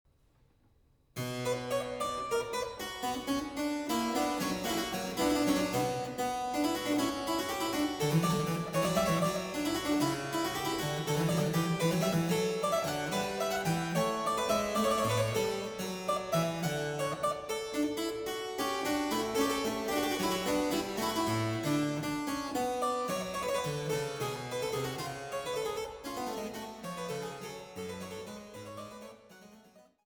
Tendrement